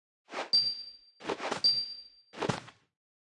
Media:Sfx_Anim_Super_Archer Queen.wavMedia:Sfx_Anim_Ultra_Archer Queen.wavMedia:Sfx_Anim_Ultimate_Archer Queen.wav 动作音效 anim 在广场点击初级、经典、高手、顶尖和终极形态或者查看其技能时触发动作的音效
Sfx_Anim_Super_Archer_Queen.wav